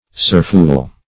surphul - definition of surphul - synonyms, pronunciation, spelling from Free Dictionary Search Result for " surphul" : The Collaborative International Dictionary of English v.0.48: Surphul \Sur"phul\, v. t. To surfel.